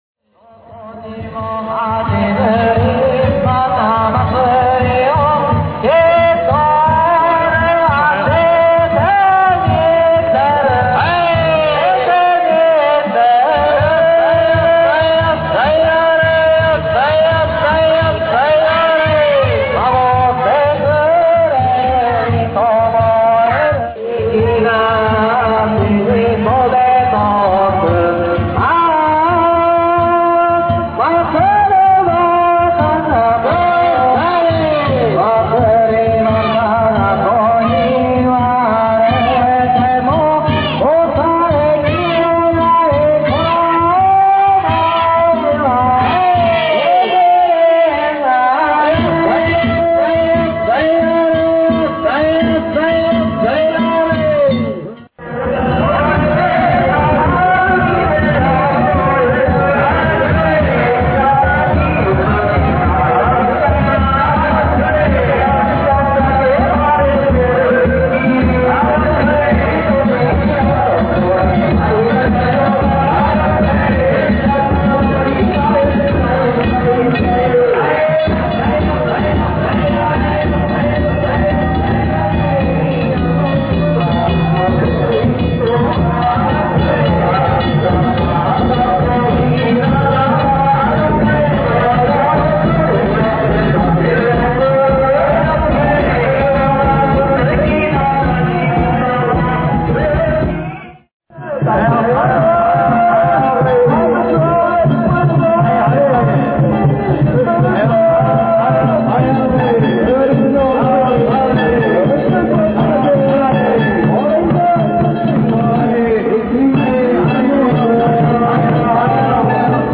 9時頃になると、東からも西からも曳き唄の声が聞こえてきました♪
桐山は曳き唄歌いながらの曳行です。
特徴のある鐘の音を響かせながら進みます。